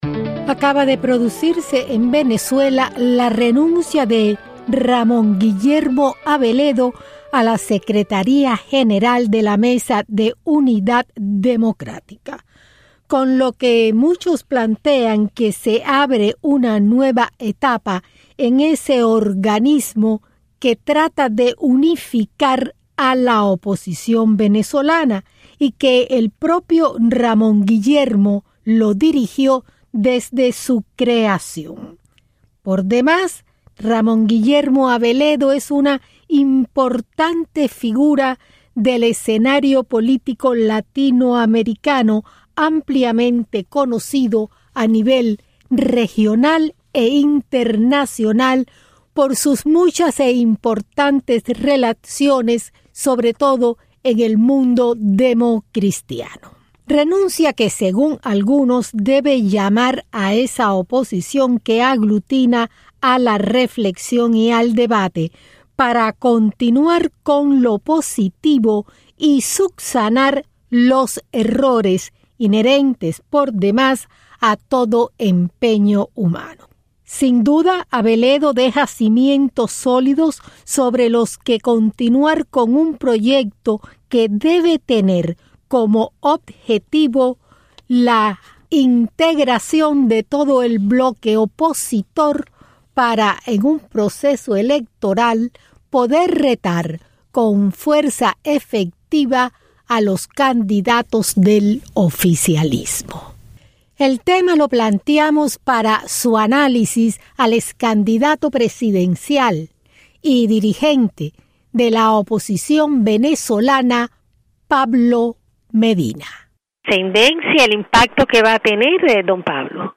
Renuncia a la Mesa de unidad en Venez Ramon Guillermo Aveledo. Entrev a Pablo Medina